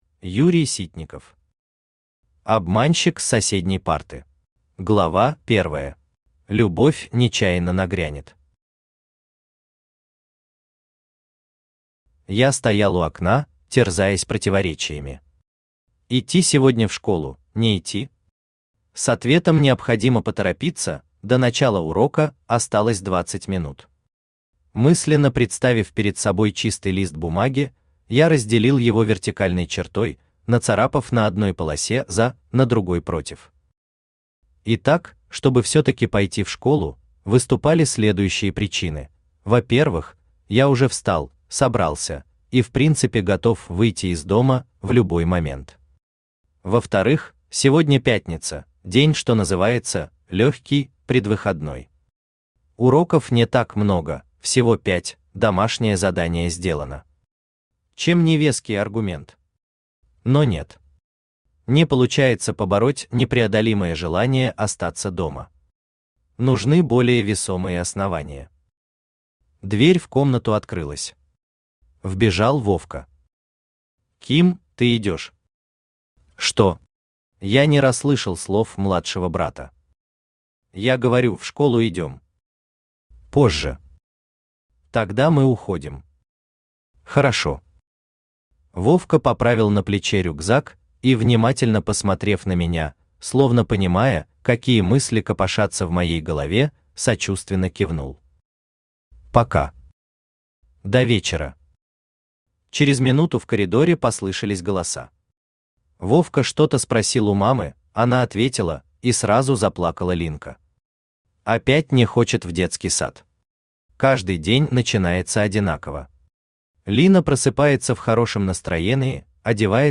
Аудиокнига Обманщик с соседней парты | Библиотека аудиокниг
Aудиокнига Обманщик с соседней парты Автор Юрий Вячеславович Ситников Читает аудиокнигу Авточтец ЛитРес.